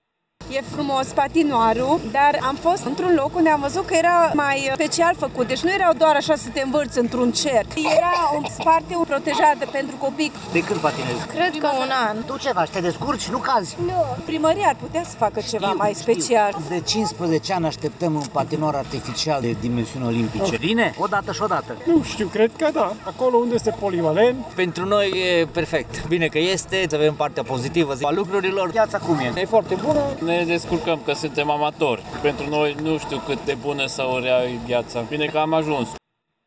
Mulți dintre cei prezenți la patinoar sunt începători, deci nu au mari pretenții de la calitatea gheții, însă unii și-ar dori să existe o zonă separată pentru protecția copiilor: